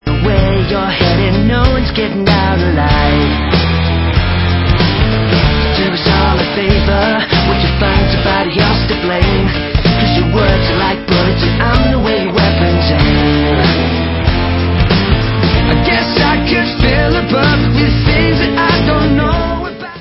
britští poprockeři